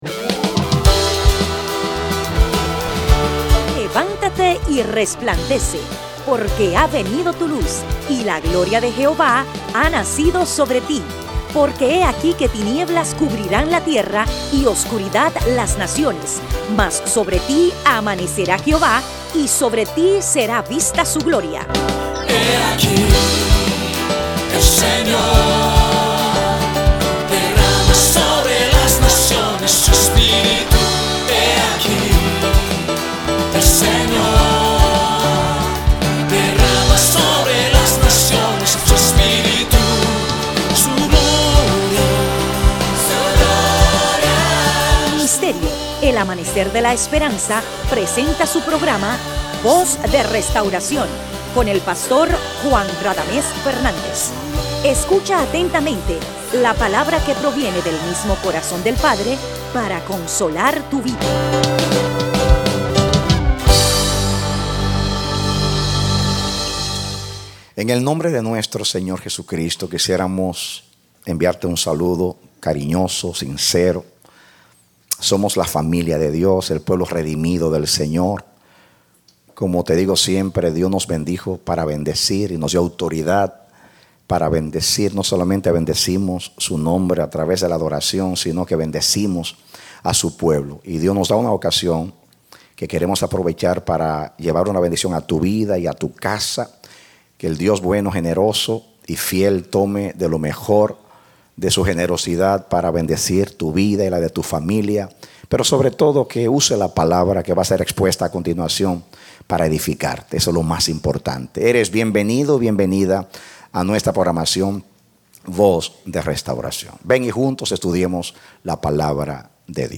Serie Parte A Predicado Enero 13, 2013